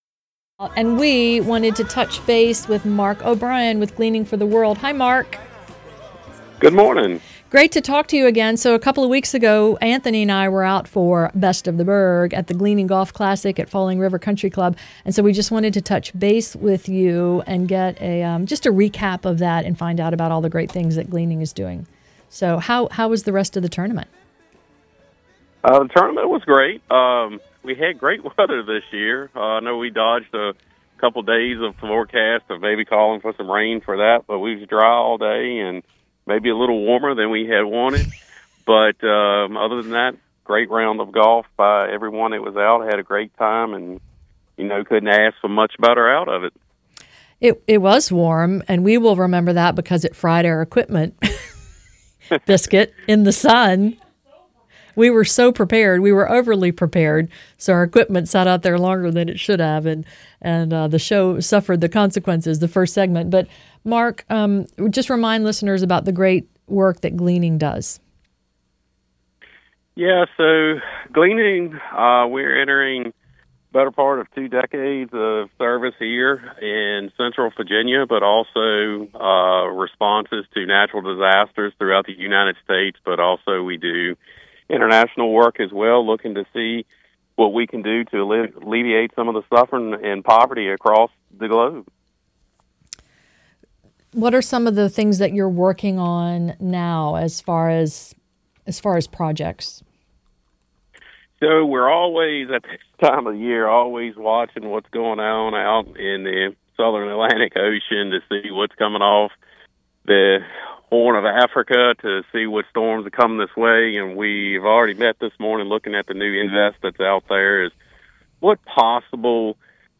WLNI The Morningline – GFTW Interview October 6th, 2025